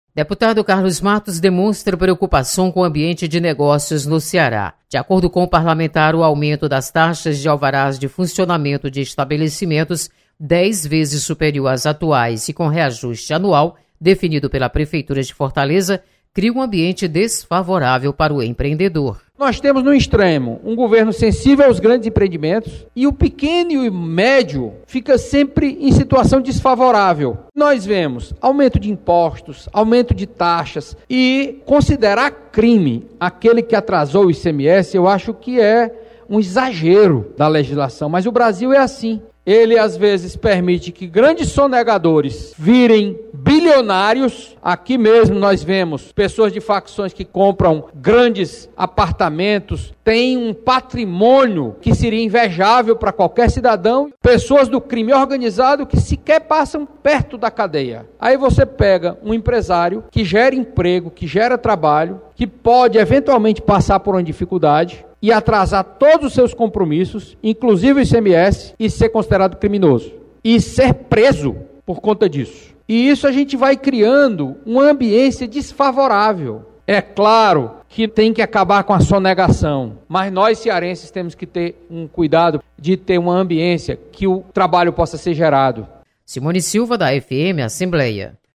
Deputado Carlos Matos mostra preocupação com aumento de taxas de alvarás. Repórter